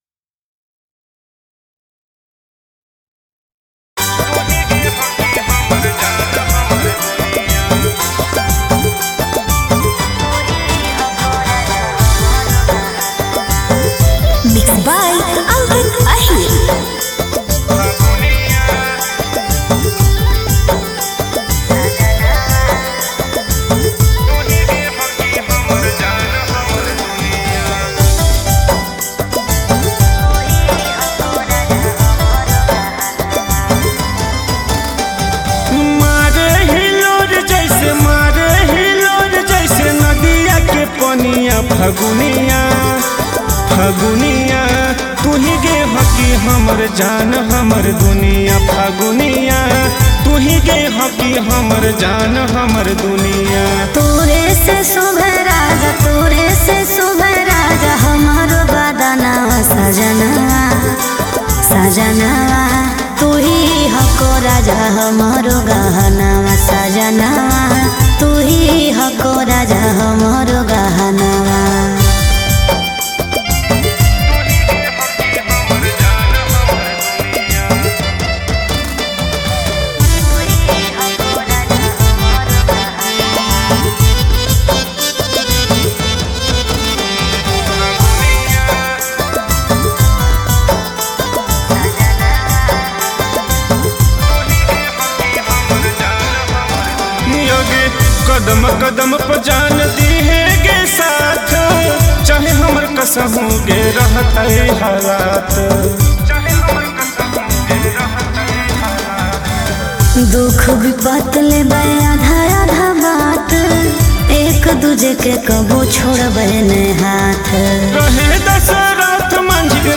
Category: Bhojpuri